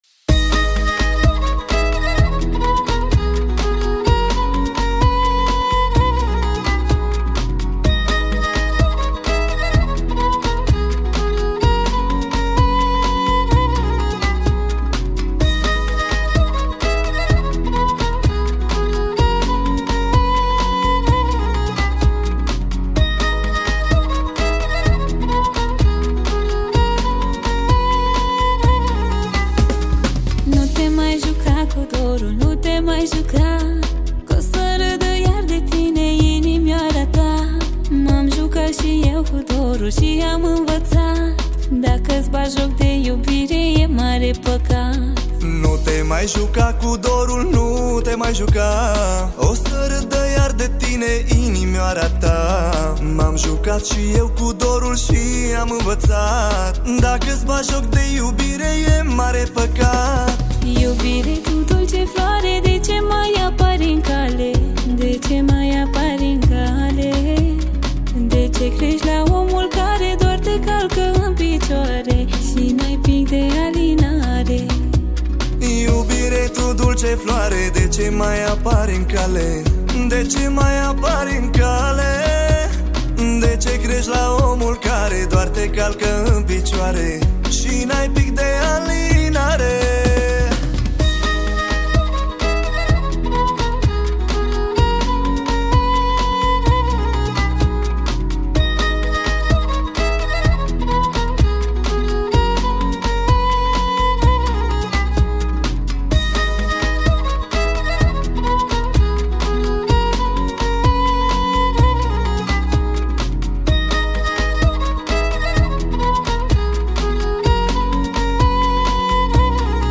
Data: 23.10.2024  Romaneasca Hits: 0